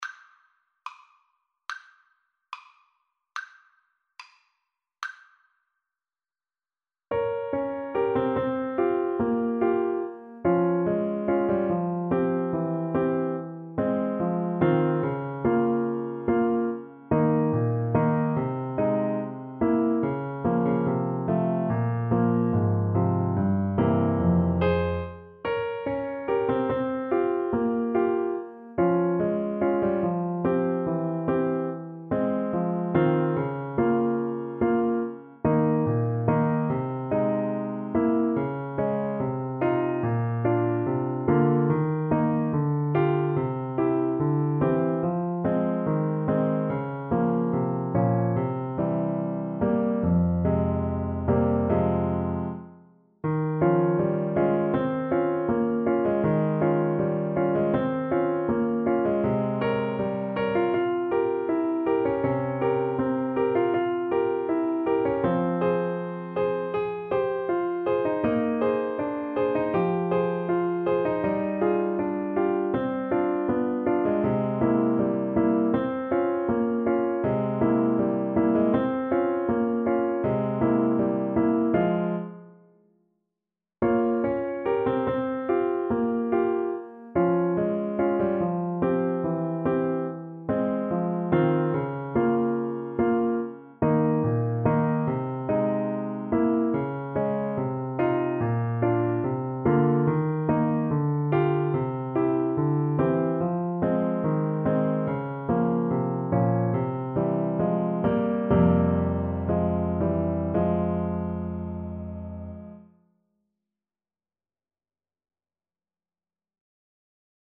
~ = 72 Andantino (View more music marked Andantino)
Classical (View more Classical French Horn Music)